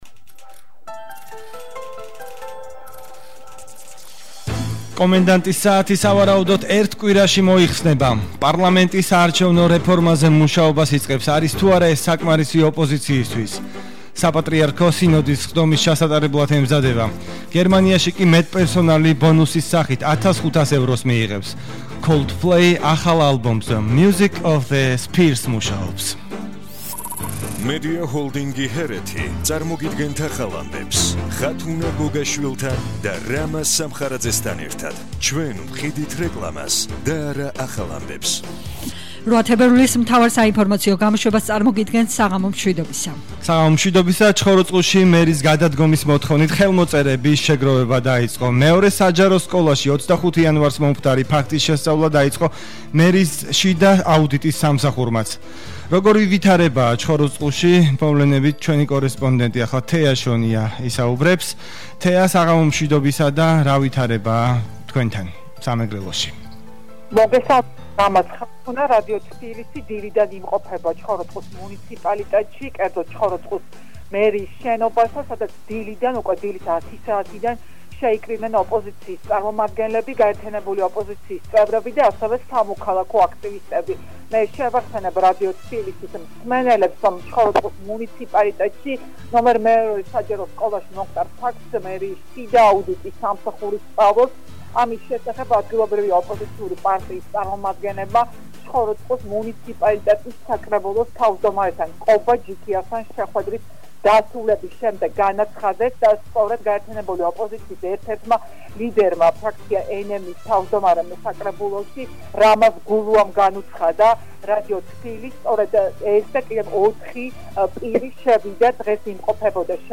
მთავარი საინფორმაციო გამოშვება –08/02/21 - HeretiFM